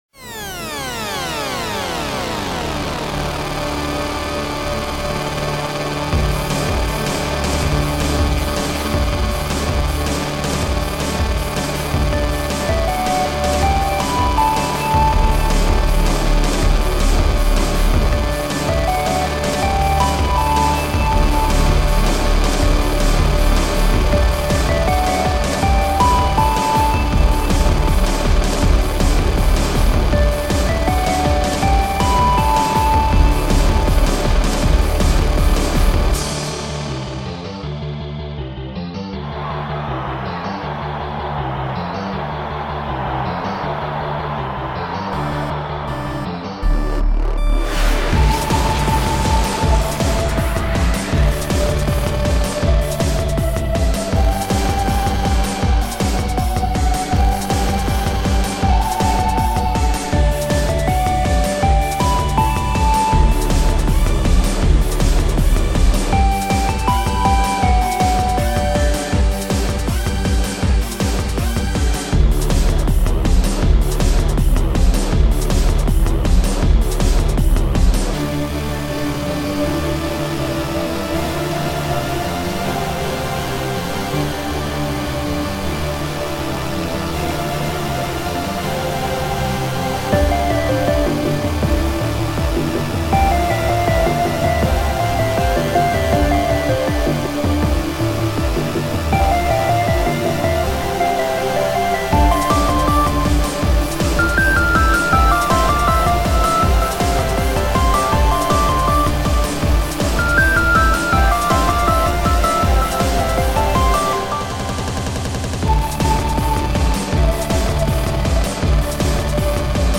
My entry for the remix war.